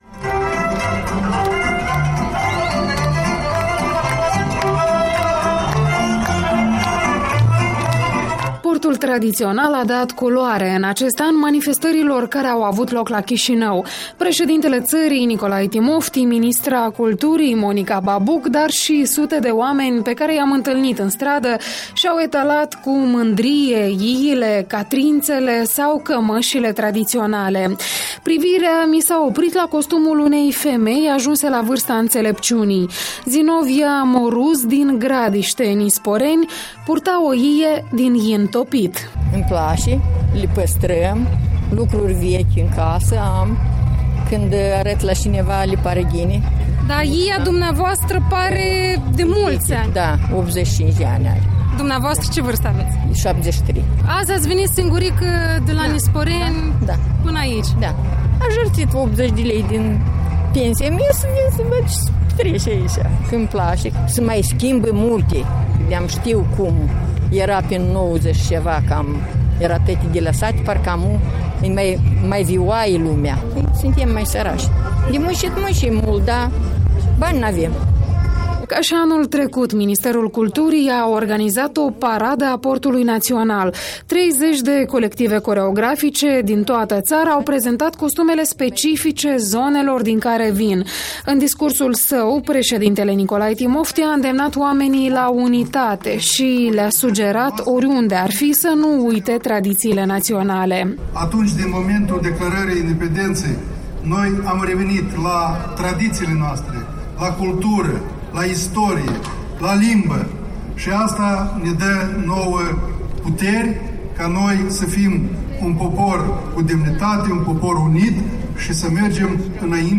Opinii culese pe străzile capitalei despre independenţa şi viitorul R. Moldova